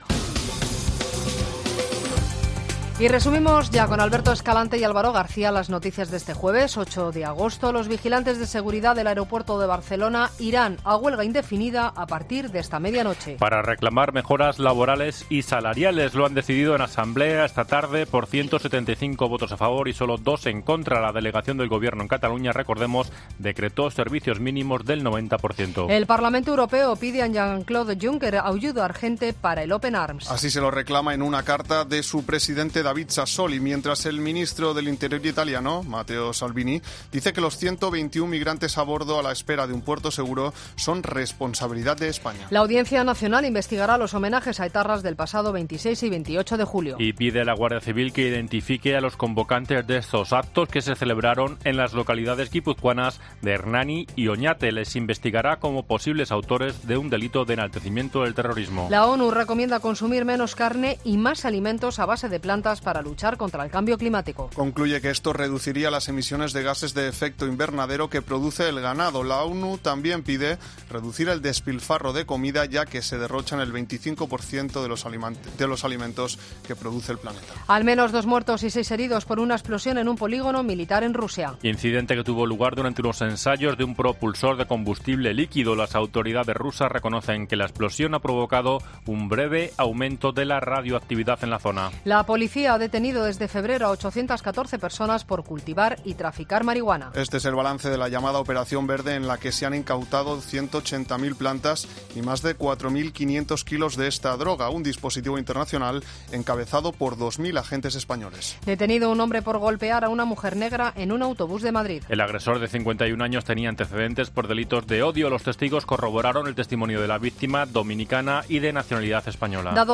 Boletín de noticias de COPE del 8 de agosto de 2019 a las 20.00 horas